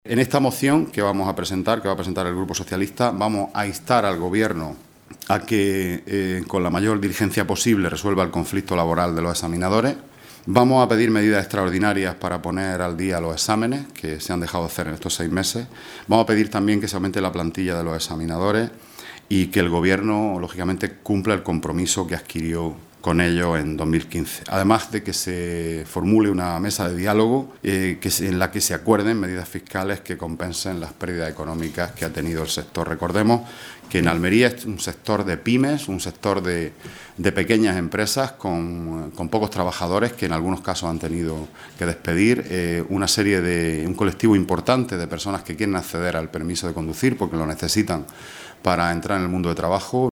Rueda de prensa que ha ofrecido el portavoz del Grupo Socialista en la Diputación Provincial, Juan Antonio Lorenzo